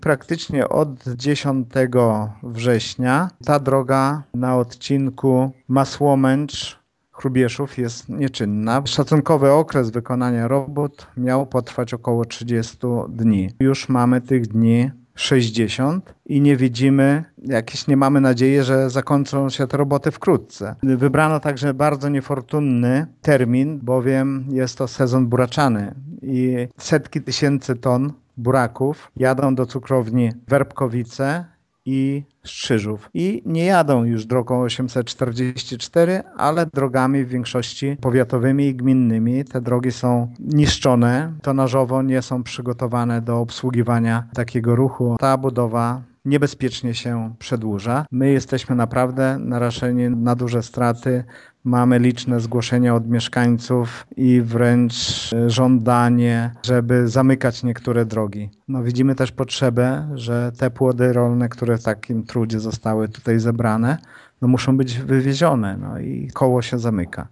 - Obecna sytuacja nijak się ma do tego, o czym wykonawca robót informował nas na początku września - mówi Informacyjnej Agencji Samorządowej Lech Szopiński: